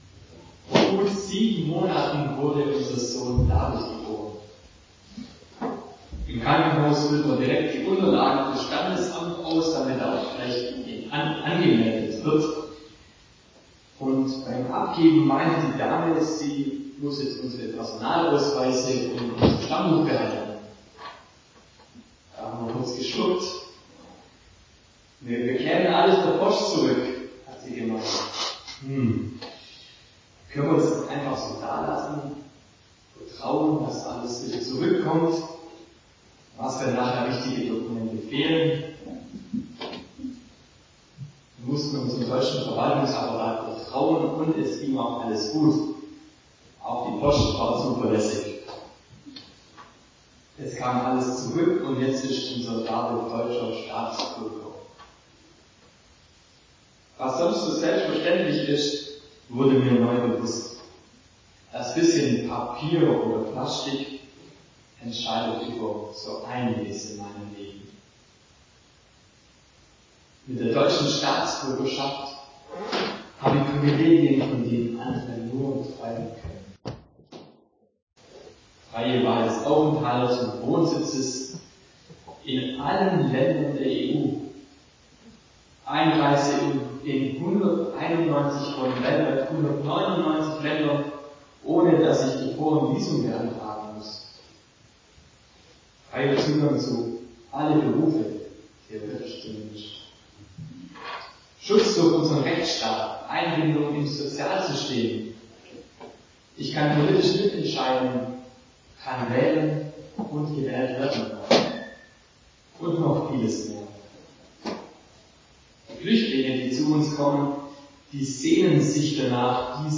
Predigt
Die Aufnahmequalität ist leider vermindert, wir empfehlen die Predigt nachzulesen: Das Skript zum Nachlesen hier als PDF zum Download: Im Himmel daheim – wir sind Himmelsbürger